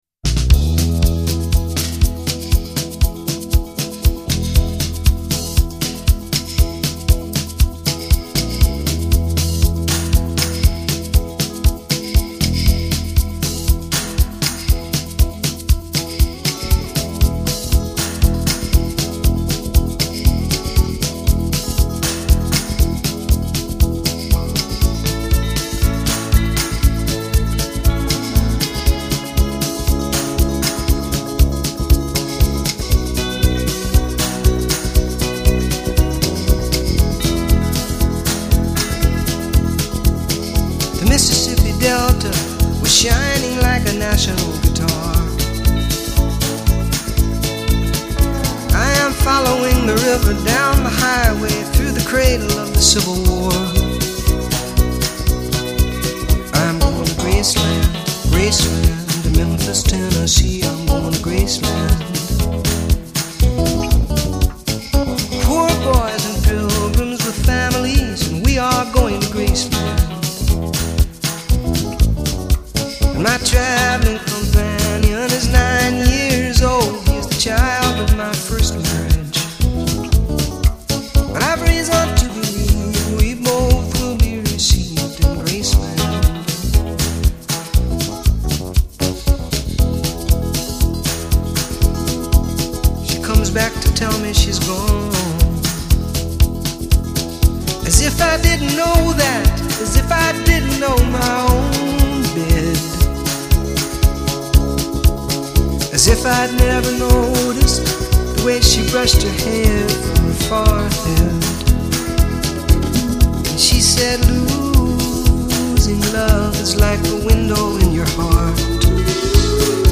专辑类型：摇滚
混重、自然、奔放、内省是它给人们带来的感觉。